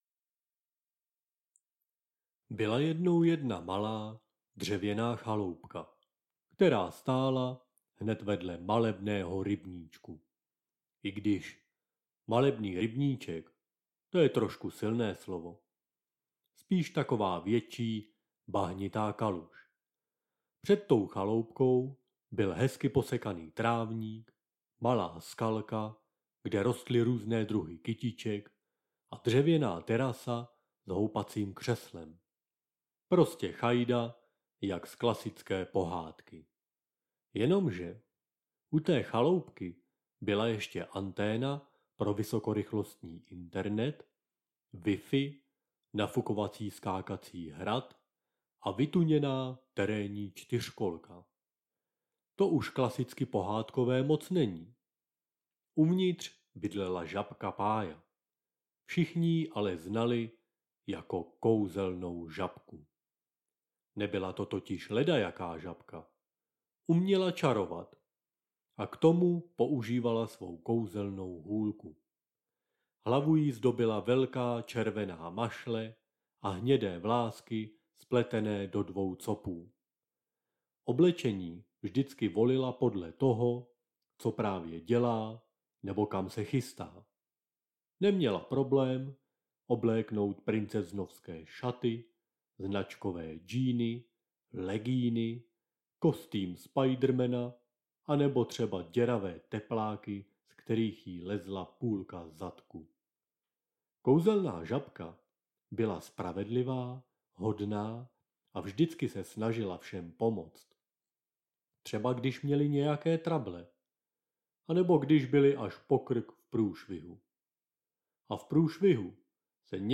• AudioKniha ke stažení Kouzelná žabka- audio pohádky
Kouzelná žabka- audio pohádky
Kouzelná žabka-legrační audio pohádky na dobrou noc
kouzelna-zabka-audio-pohadky.mp3